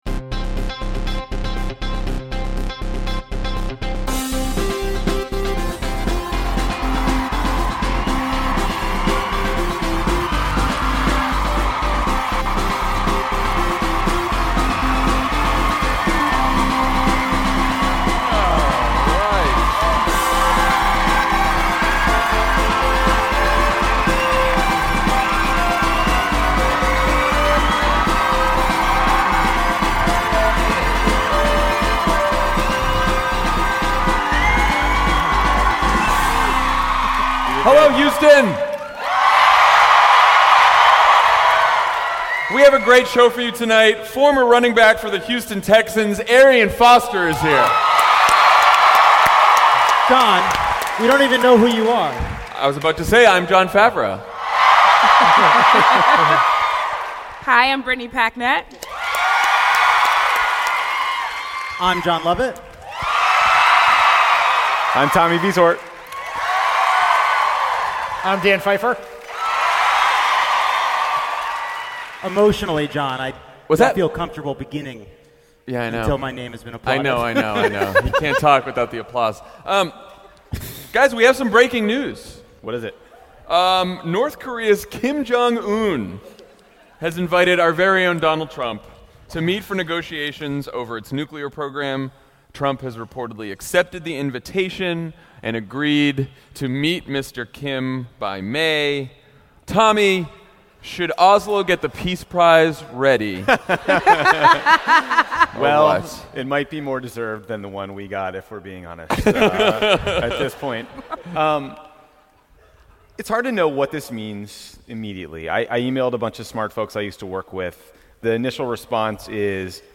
“Give Ruth a break.” (LIVE from Houston)
Trump accepts Kim Jong Un’s invite to a Madman vs Madman summit, Mueller has a new witness, and Democrats look to turn Texas blue. Former Houston Texan Arian Foster joins Jon, Jon, Tommy, Dan, and Brittany Packnett on stage live in Houston, Texas.